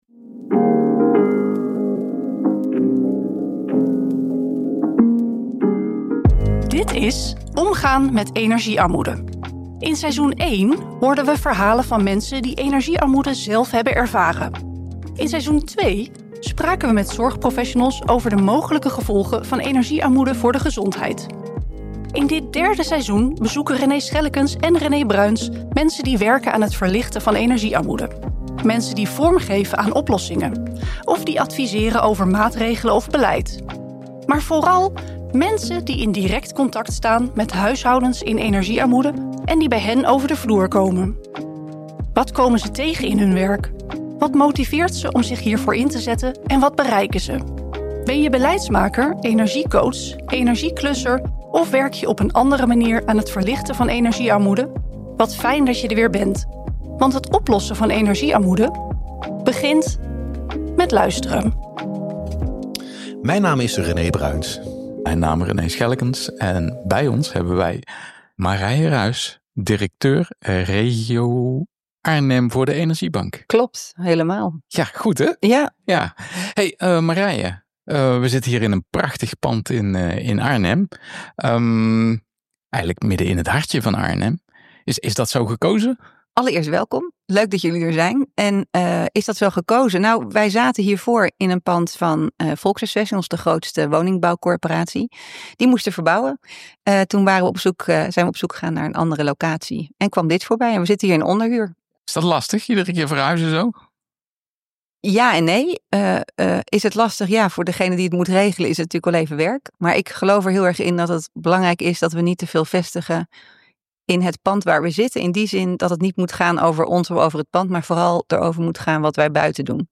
In deze vierdelige podcast-reeks luistert u naar verhalen van mensen die leefden in energiearmoede.